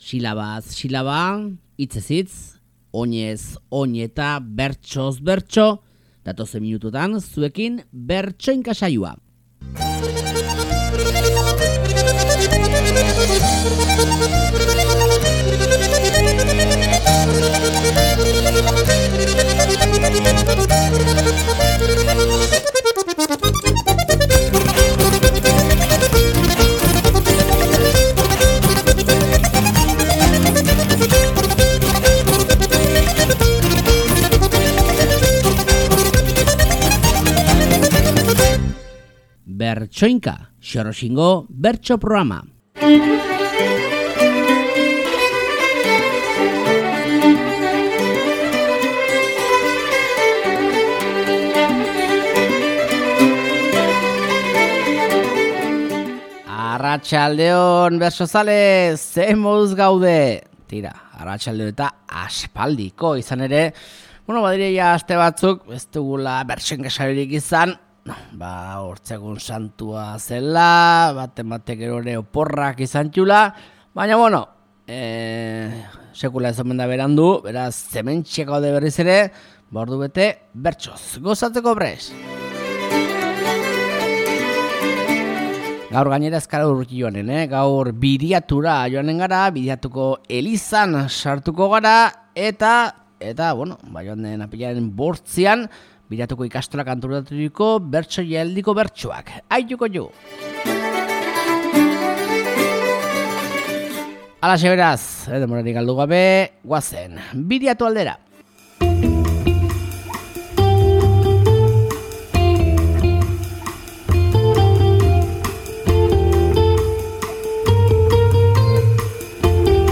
Biriatuko Ikastolak antolaturiko bertso-jaialdiko bertsoak, aste honetako Bertsoinka saioan.